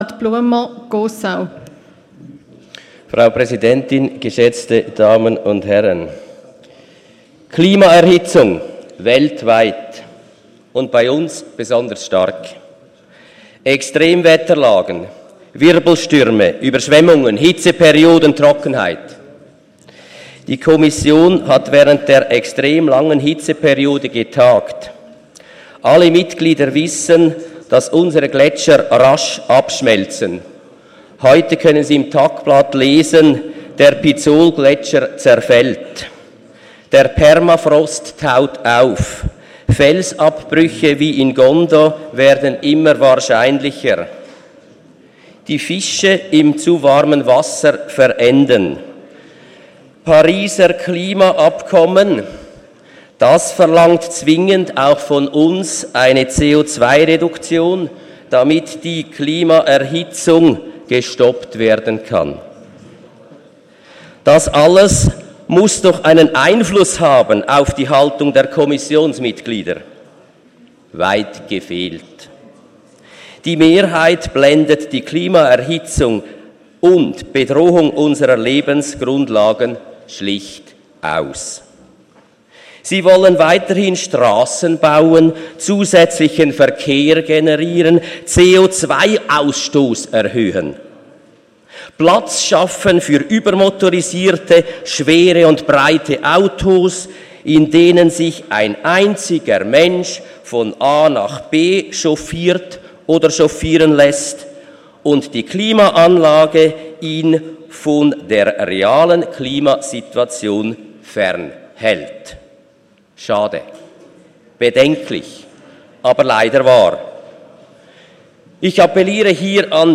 18.9.2018Wortmeldung
Session des Kantonsrates vom 17. bis 19. September 2018